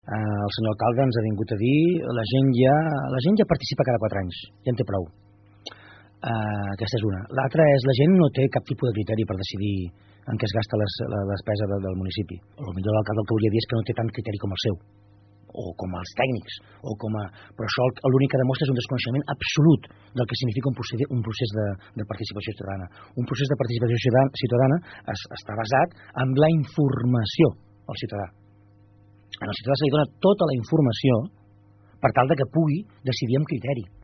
Francesc Alemany, regidor d’ERC a l’Ajuntament de Palafolls. – Imatge d’arxiu
Aquest dimecres passava pel programa Assumptes Interns Francesc Alemany, l’últim dels regidors d’ERC a l’Ajuntament de Palafolls que faltava per ser entrevistat.